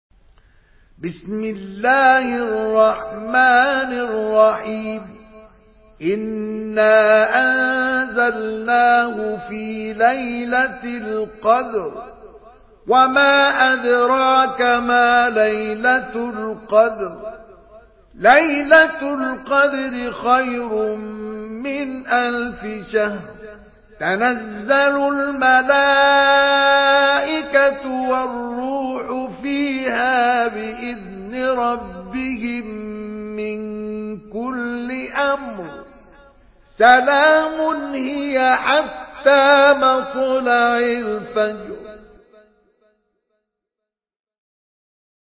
Surah Kadir MP3 in the Voice of Mustafa Ismail in Hafs Narration
Murattal